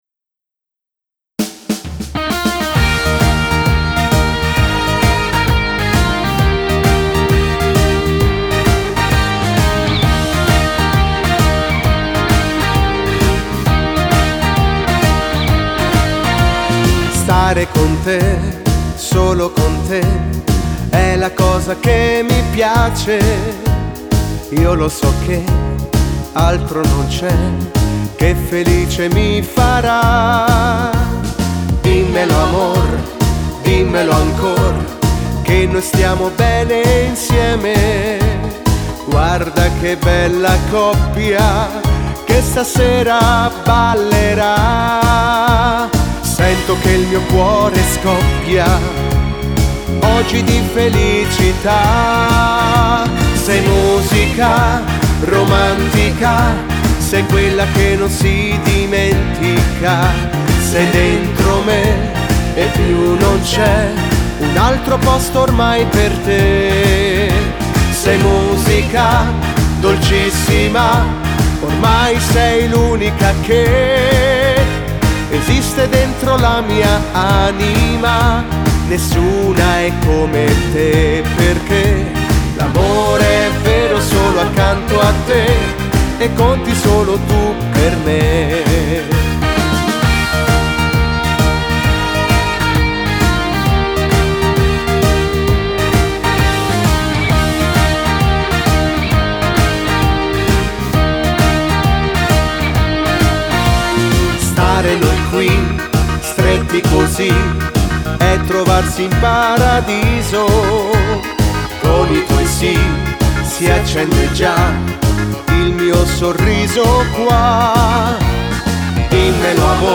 Hully gully